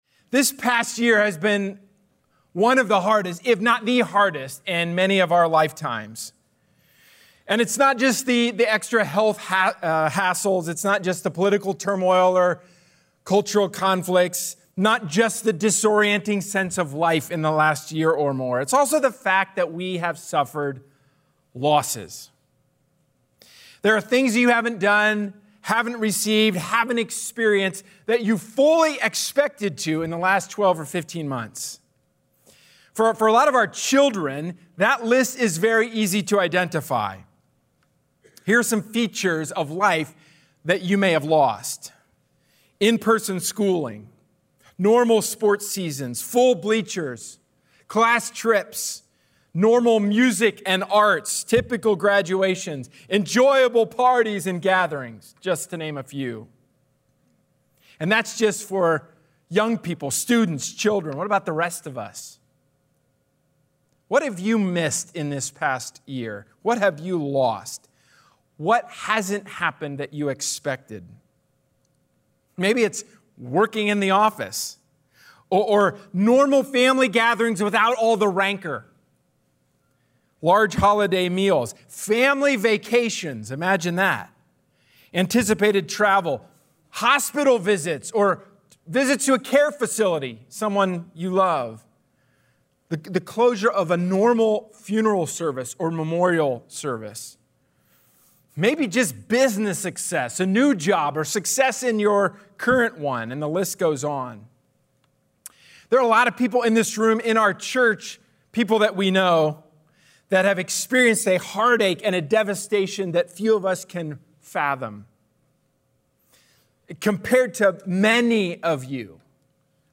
A sermon from the series "James: Faith/Works."